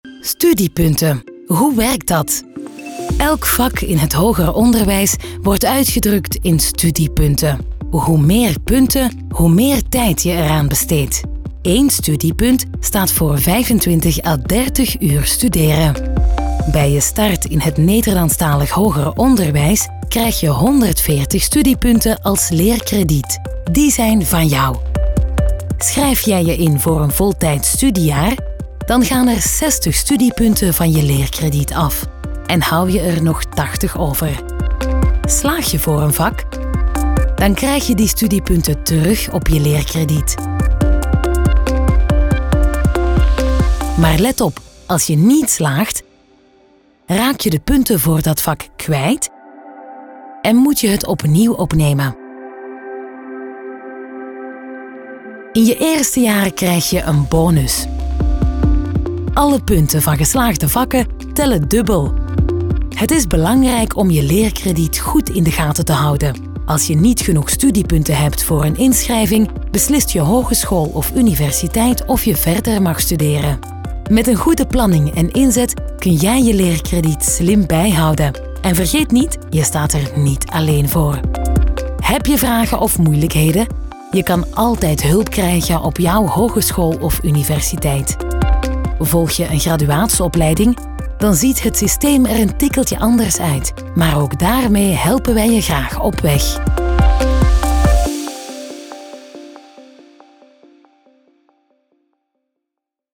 Diep, Jong, Speels, Veelzijdig, Warm
Explainer